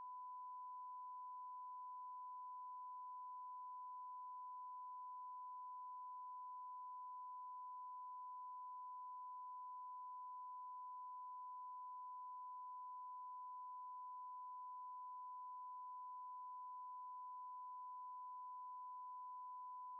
Um das entsprechende Klangbeispiel einzufügen (ein 1000 Hz Sinuston mit einem Pegel von -53 LUFS), wechseln Sie im Eingabefeld "Frage" über den Button "Quellcode" in den Quellcode und fügen Sie am Ende des Satzes folgend Zeilen hinzu: